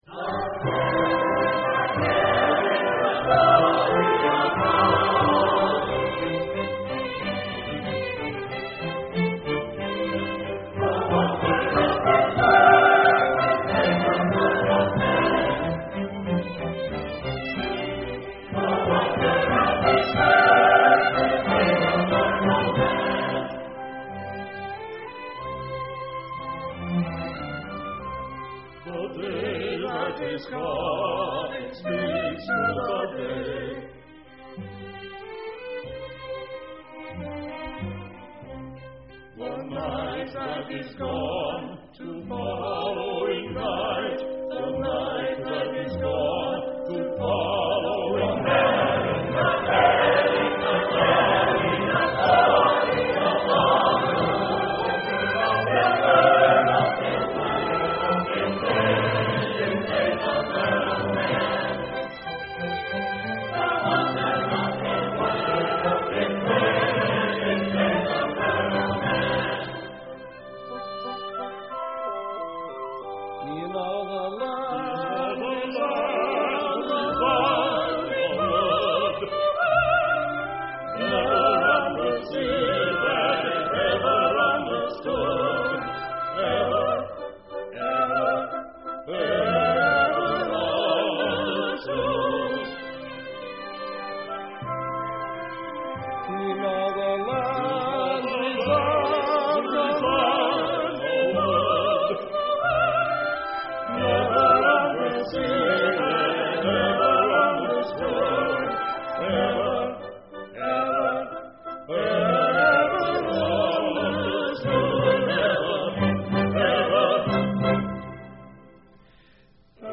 SACRED / CHRISTIAN